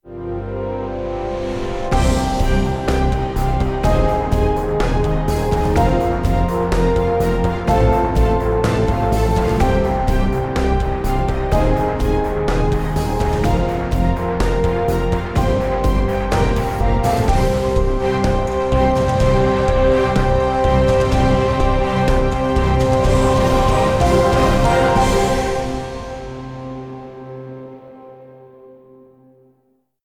Sounddesign: Event-Signation